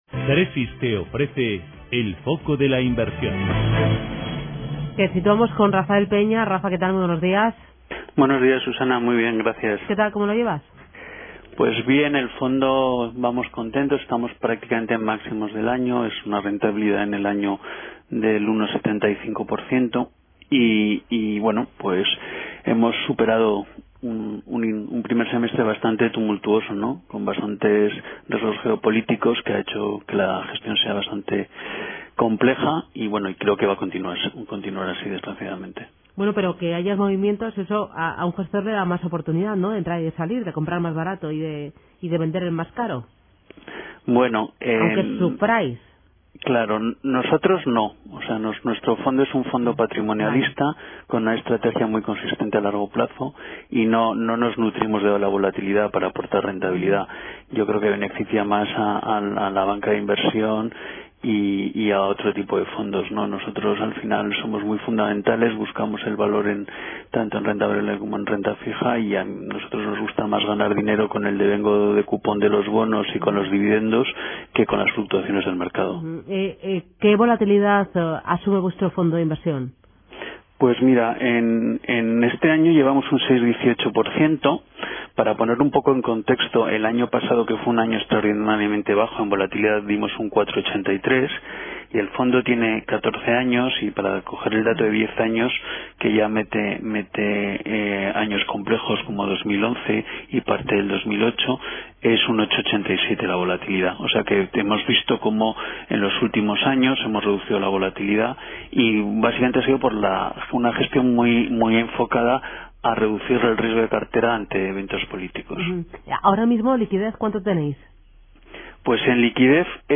En la radio
En Radio Intereconomía todas las mañanas nuestros expertos analizan la actualidad de los mercados.